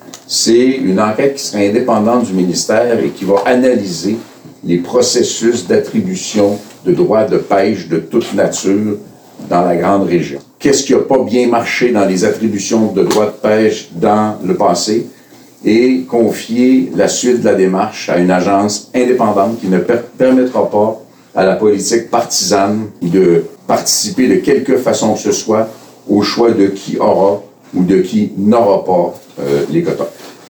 Habitué de la pointe gaspésienne, Yves-François Blanchet s’est présenté devant les médias accompagné d’une vingtaine de pêcheurs – principalement des homardiers – afin de faire part de ses constats en lien avec l’industrie.